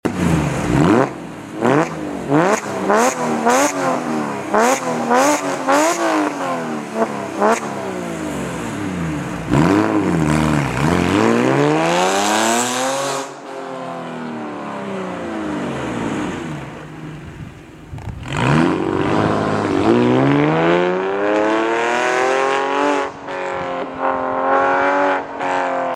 The Porsche 996 Carrera 4S. sound effects free download
LITSEN to this car. What a sound.